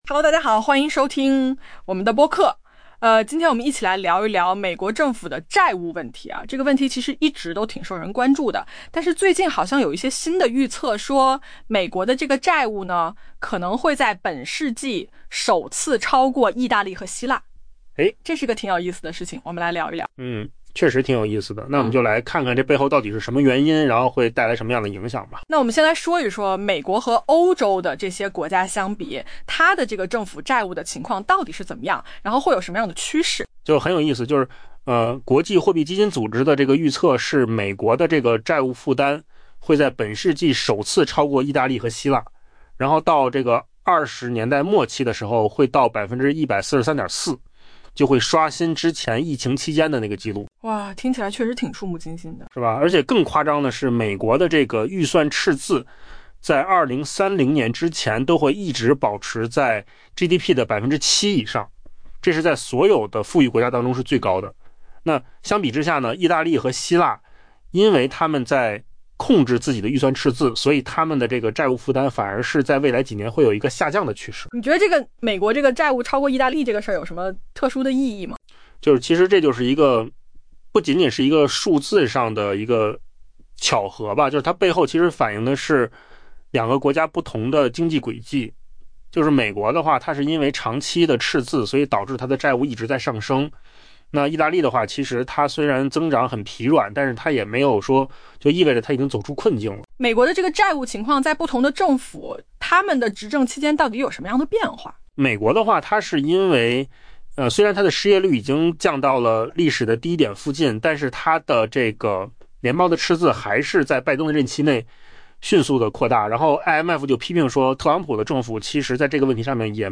AI 播客：换个方式听新闻 下载 mp3 音频由扣子空间生成 据国际货币基金组织 （IMF） 预测，美国政府债务负担有望在本世纪首次超过意大利和希腊，凸显出美国公共财政的糟糕状况。